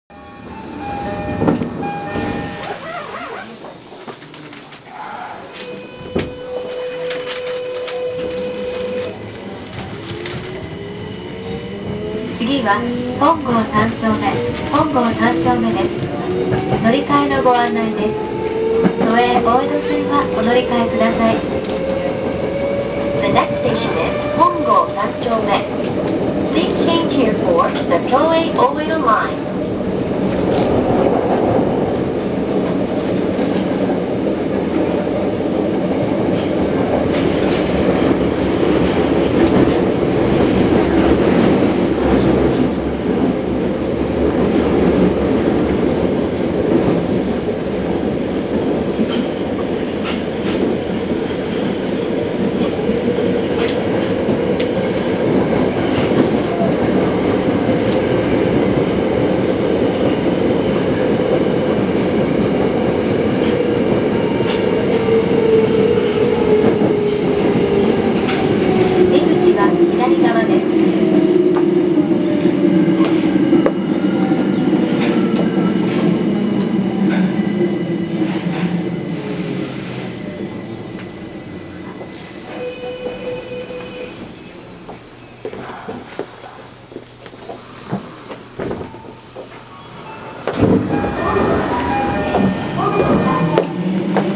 ・02系更新車走行音
【丸ノ内線】後楽園〜本郷三丁目（1分34秒：513KB）
恐らく量産車としては初の採用であろうDDMの02系更新車。最近のメトロ車の特徴の１つであるドアチャイムの変更、こちらも勿論施行されています。
02DDM_korakuen-hongo3.WAV